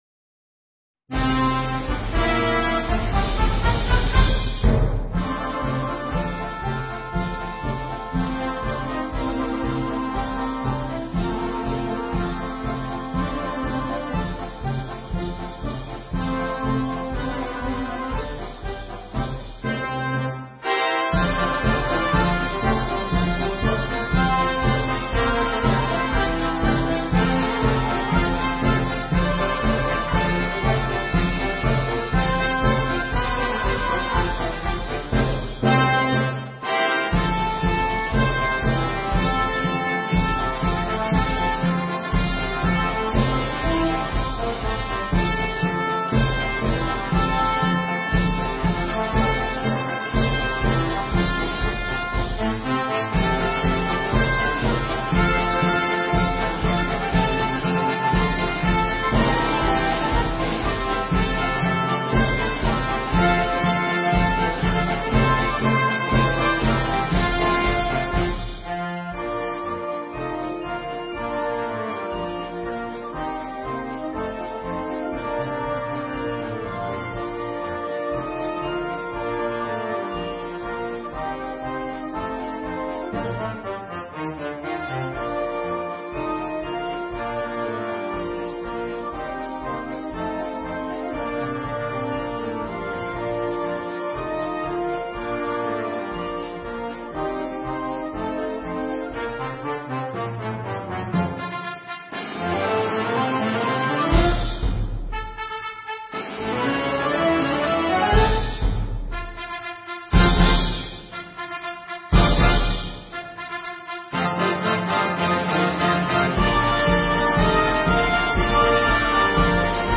MARCE DA SFILATA
MUSICA PER BANDA